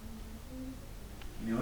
The activity included a moving "wire" in front of our DVR camera, when no one was in the room, and also we heard and collected a bunch of EVPs and two videos.
singing
singing.wav